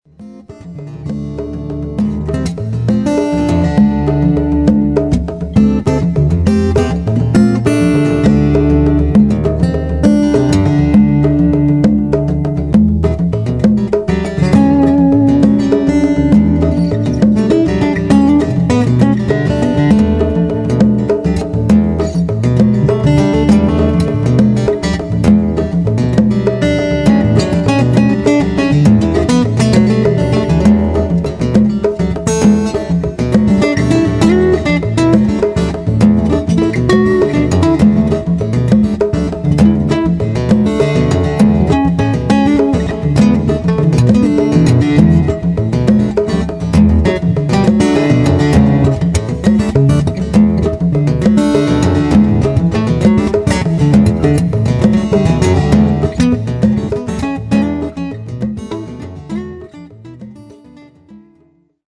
Here are a few samples of my guitar playing: (I plan to add more pictures along with some clips from old bands when I can get time to go through my stored memorabilia)
Fingerstyle with percussion - The Crossing (original)